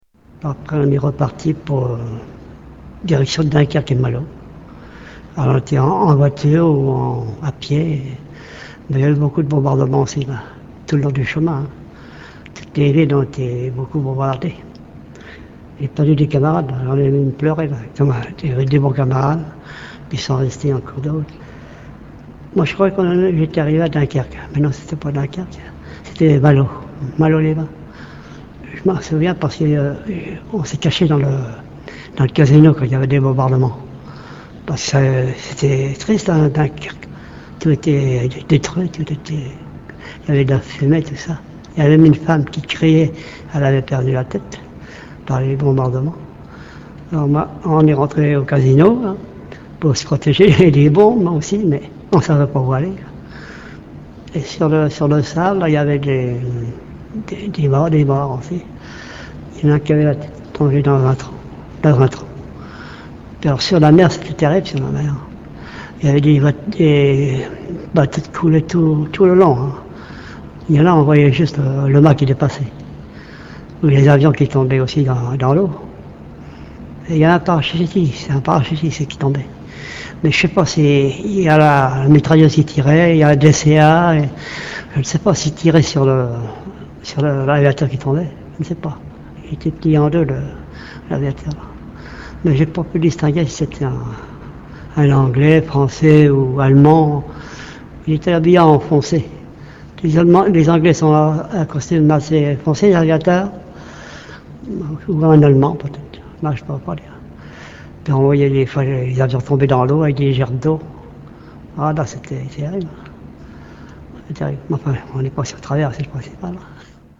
Tags: The Dunkirk Survivors Dunkirk Survivors Dunkirk Dunkirk Survivor interviews World war 2